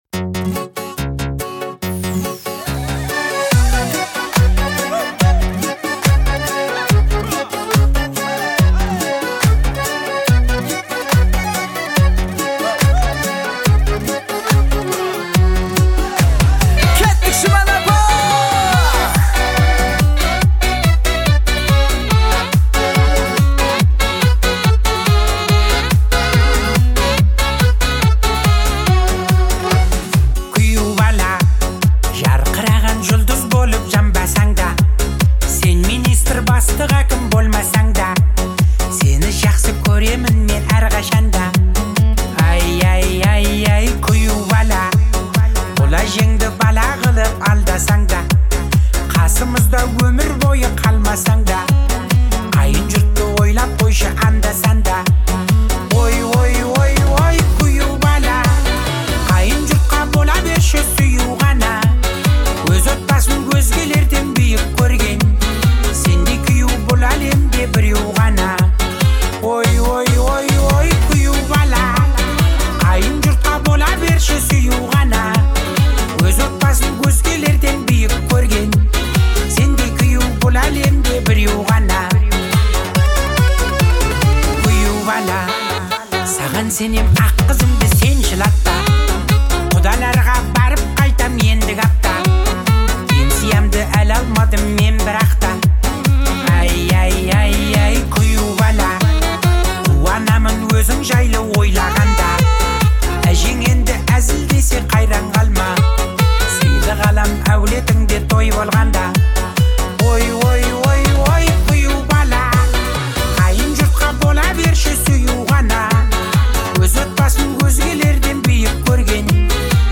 это яркий пример казахской народной музыки